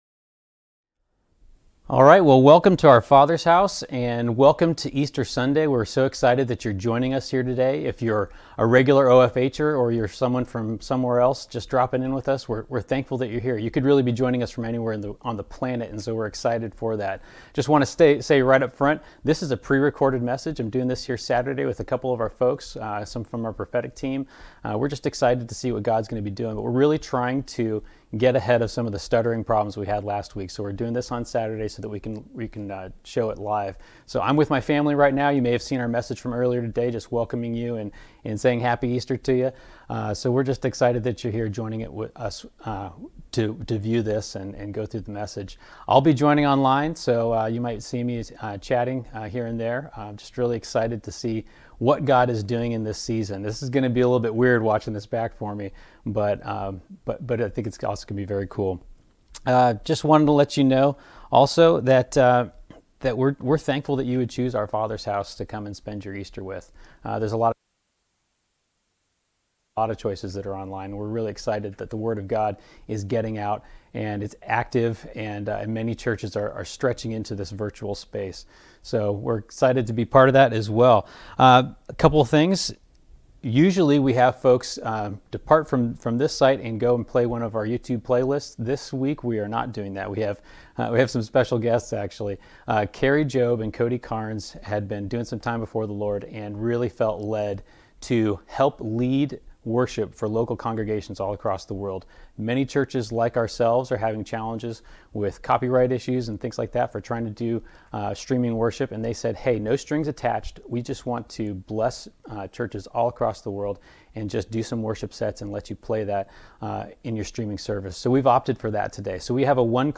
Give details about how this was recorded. We welcome you to the Our Father's House online service. Our worship stream this morning is used by permission.